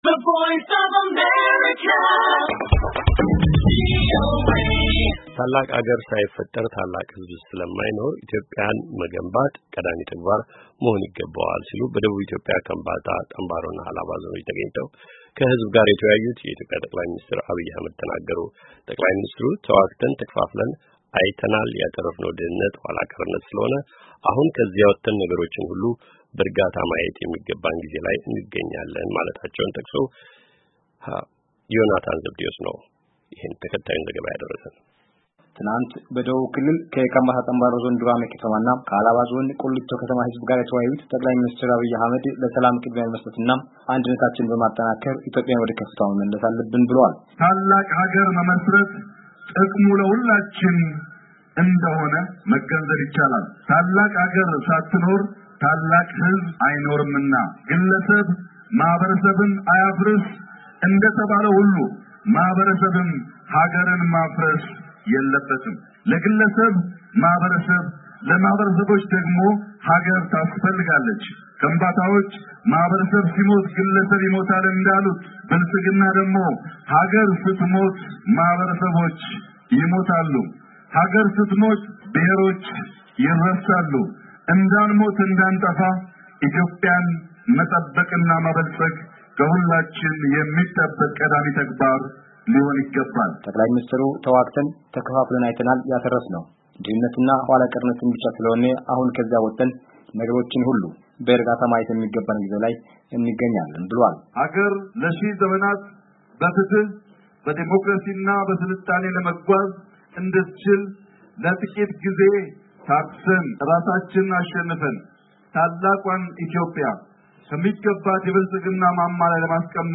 ታላቅ ሃገር ሳይፈጠር ታላቅ ህዝብ ስለማይኖር ኢትዮጵያ መገንባት ቀዳሚ ተግባር መሆን እንደሚገባ በደቡብ ክልል ካምባታ ፤ ጣምባሮና ሃላባ ዞኖች ተገኝተው ከህዝብ ጋራ የተወያዩ የኢትዮጵያ ጠቅላይ ሚኒስትር ዐብይ አህመድ ወቅት ተናገሩ።